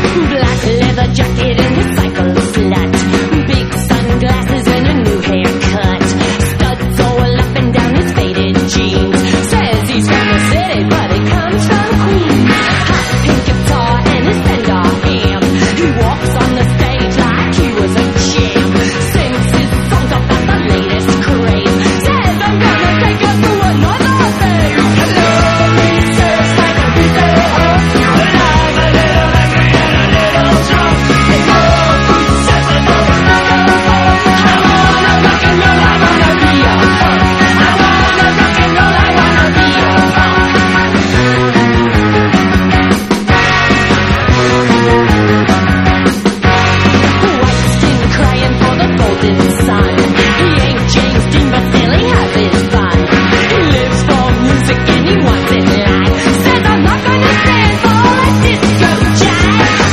EASY LISTENING / VOCAL / MAMBO / OLDIES / POPCORN